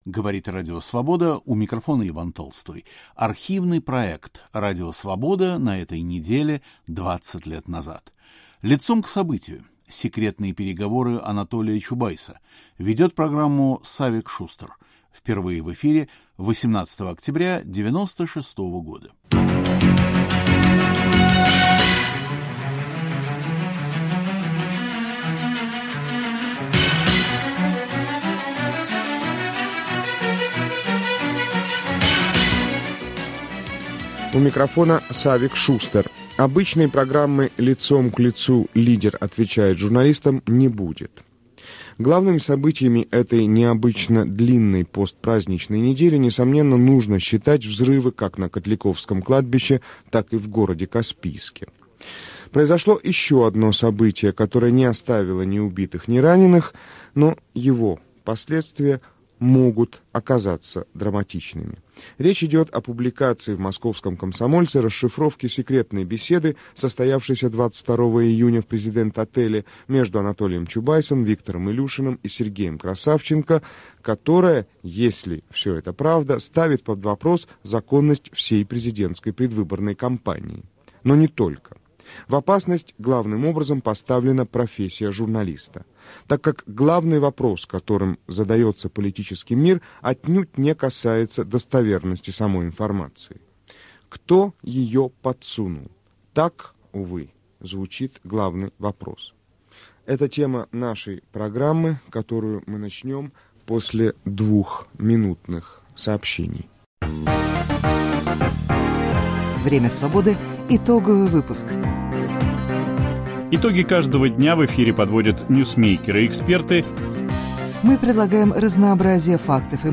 Программу ведет Савик Шустер.